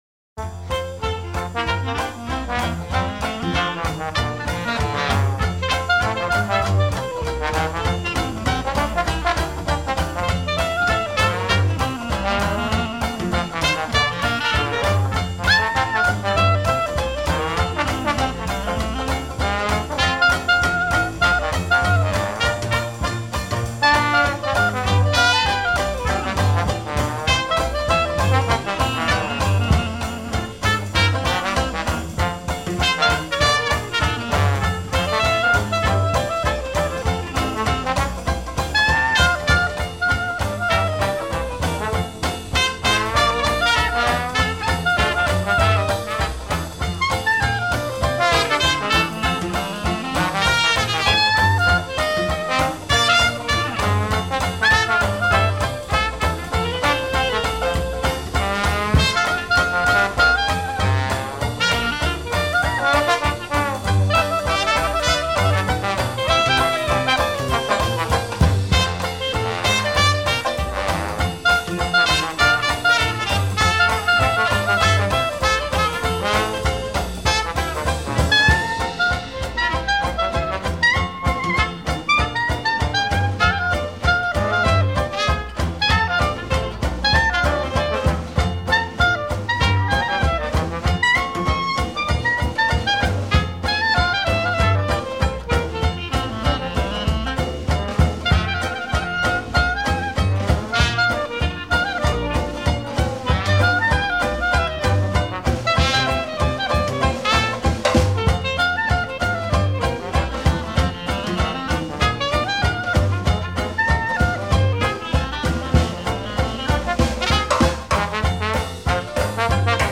Traditional old-school New Orleans Dixieland jazz band
Completely mobile and unamplified